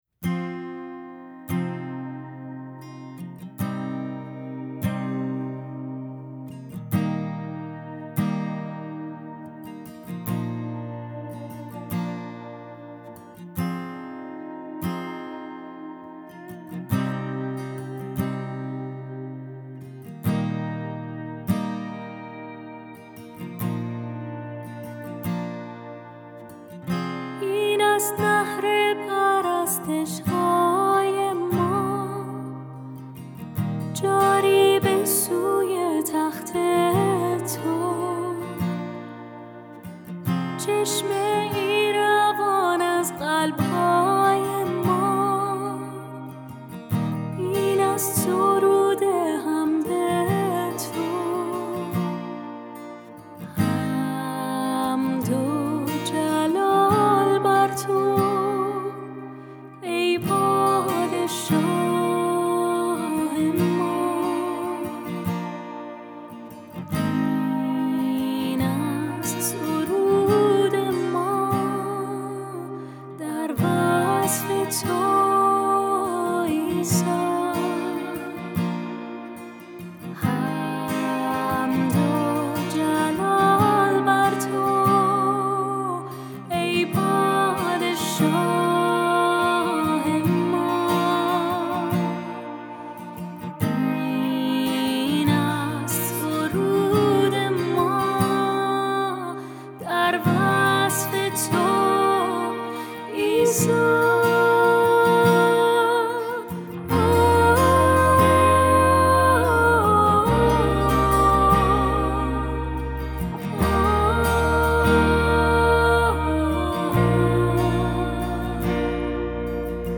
Minor